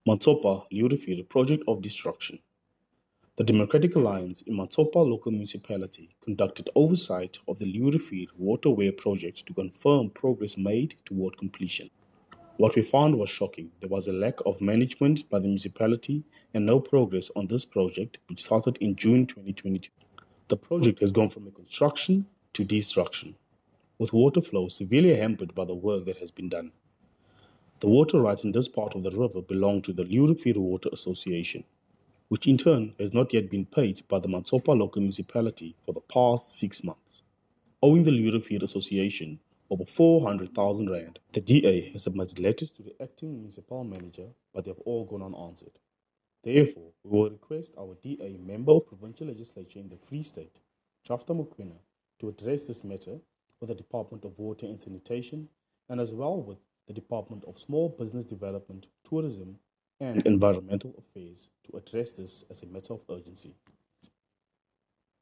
Engelse klankgrepe van Rdl. Lyle Bouwer